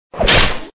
CHOMPStation2/sound/weapons/slash.ogg
Added/changed alien attack sounds.
slash.ogg